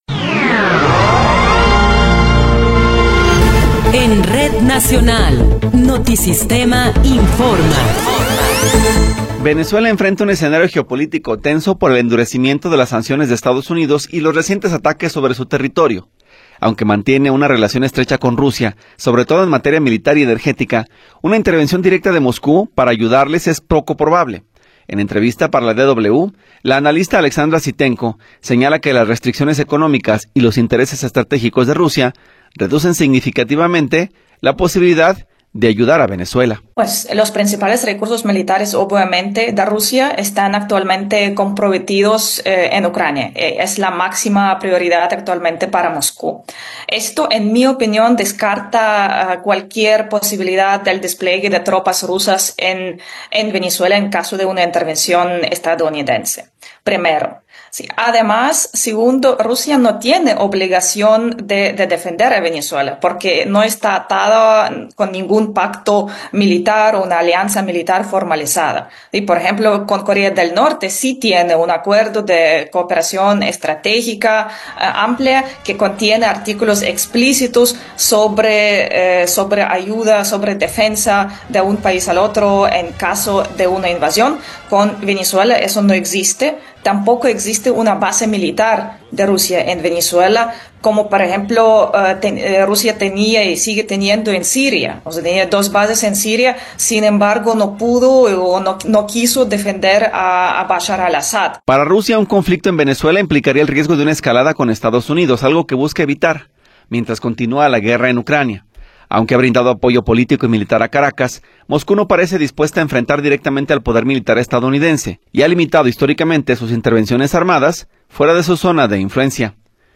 Noticiero 12 hrs. – 1 de Enero de 2026
Resumen informativo Notisistema, la mejor y más completa información cada hora en la hora.